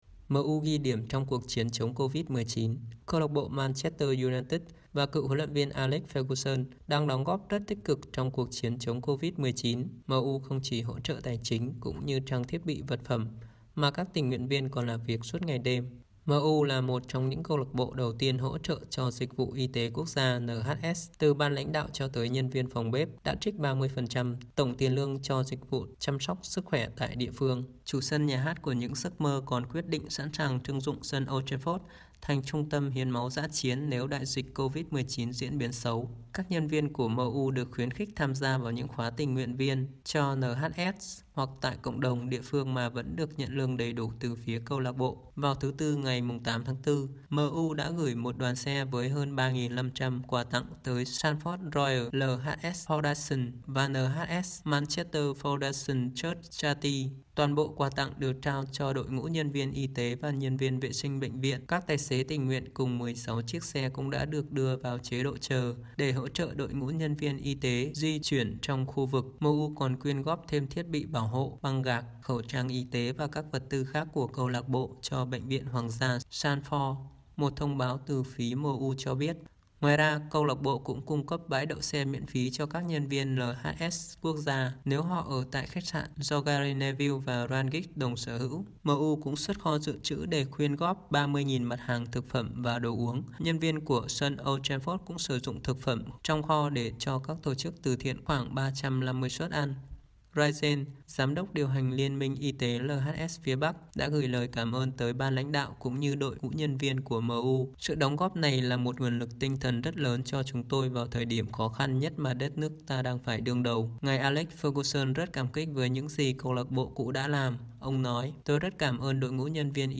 Bản tin audio